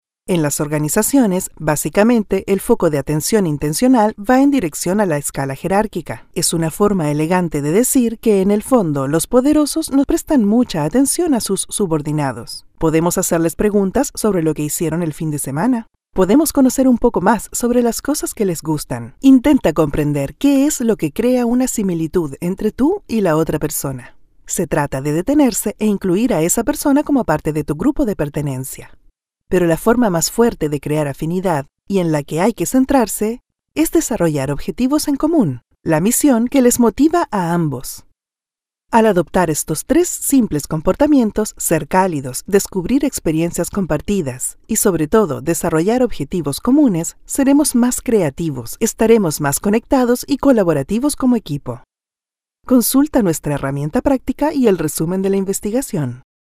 E-learning
I have a versatile and clear Voice in Universal Neutral Spanish.
Young adult or adult female voice with a perfect diction, believable.
I own a professional audio recording studio, with soundproof booth included.